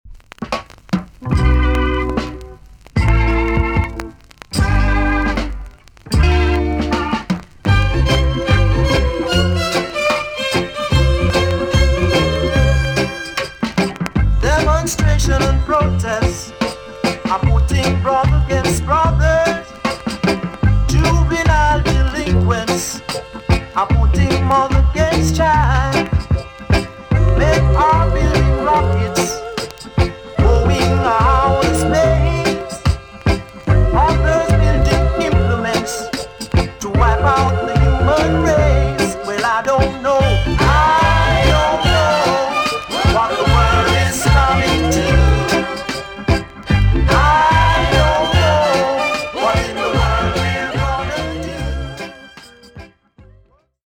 TOP >REGGAE & ROOTS
VG+ 軽いチリノイズがあります。